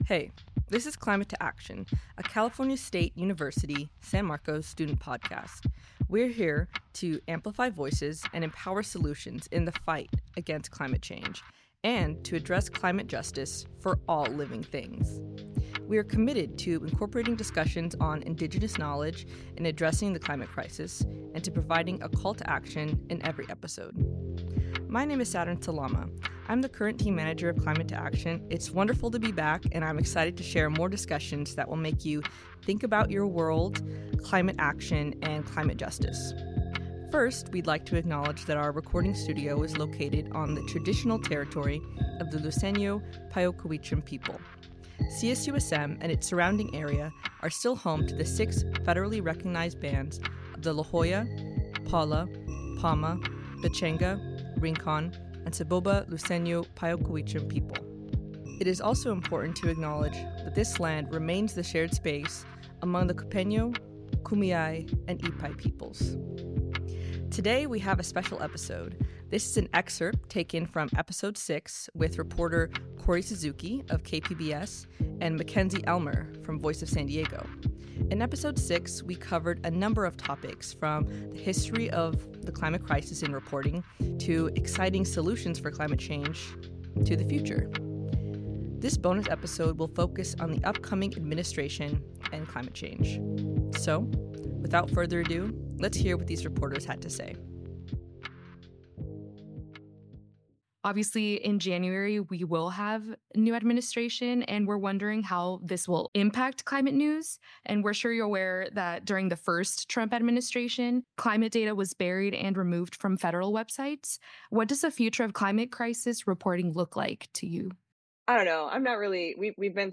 Climate to Action is produced at the CSUSM Inspiration Studios.